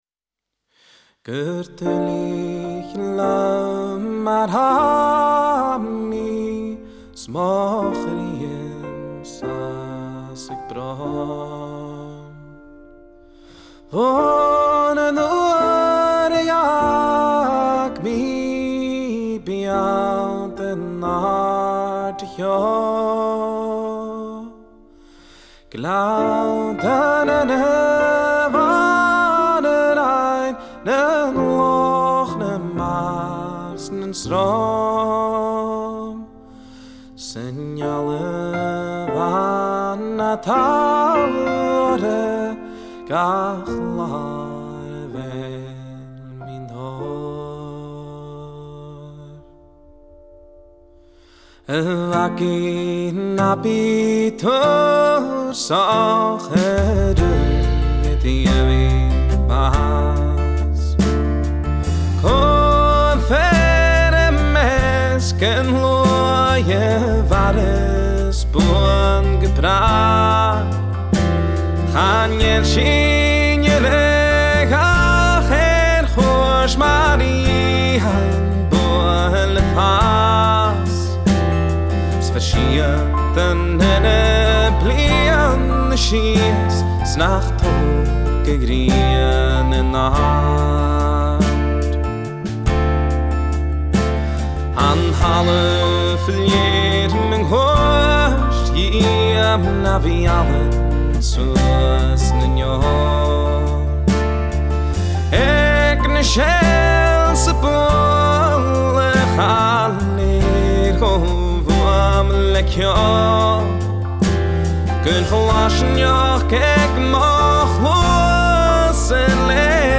Genre: Folk / World /Celtic